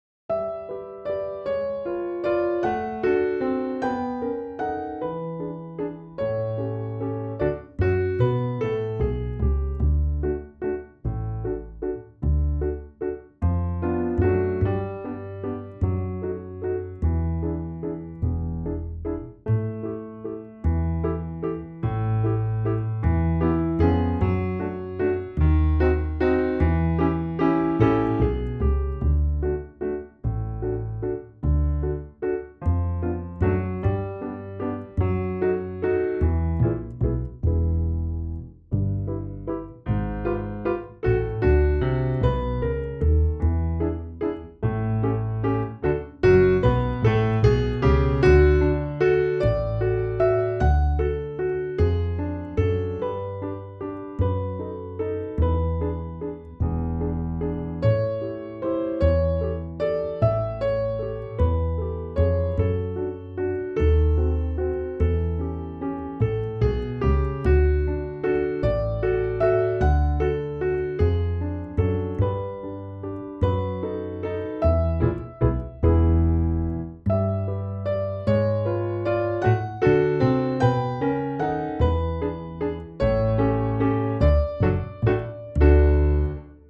Play (or use space bar on your keyboard) Pause Music Playalong - Piano Accompaniment transpose reset tempo print settings full screen
3/4 (View more 3/4 Music)
Moderato . = c. 50
D major (Sounding Pitch) (View more D major Music for Violin )